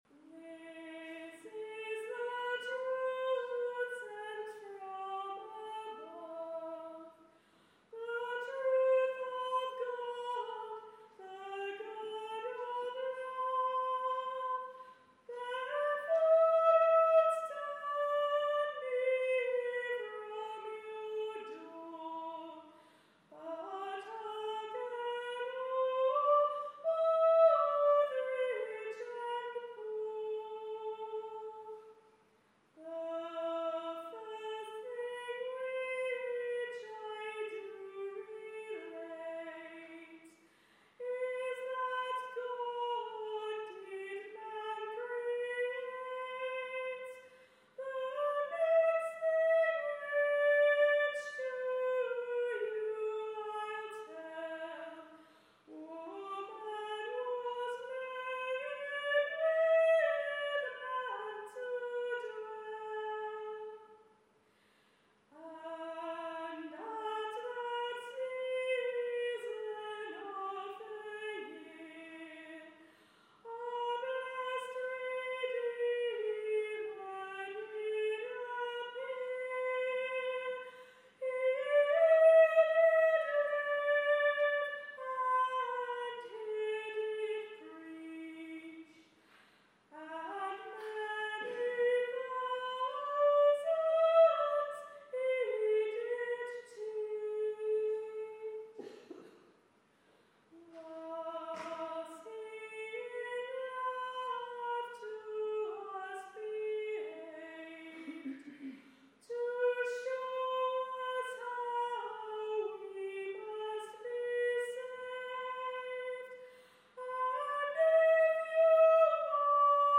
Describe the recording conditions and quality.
at an evening service